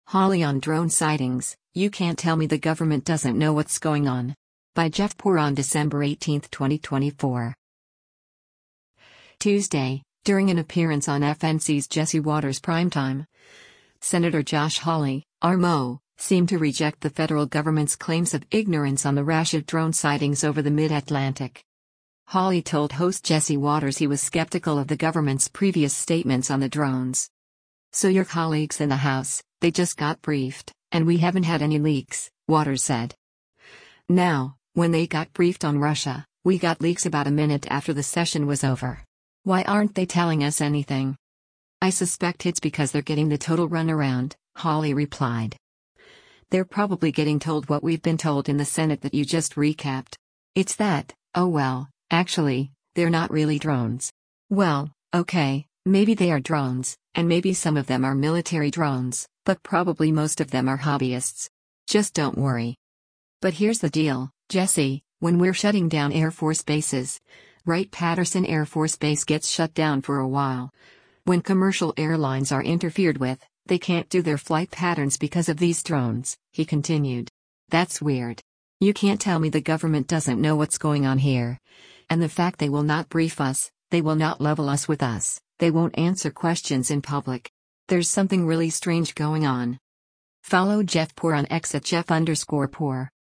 Tuesday, during an appearance on FNC’s “Jesse Watters Primetime,” Sen. Josh Hawley (R-MO) seemed to reject the federal government’s claims of ignorance on the rash of drone sightings over the mid-Atlantic.
Hawley told host Jesse Watters he was skeptical of the government’s previous statements on the drones.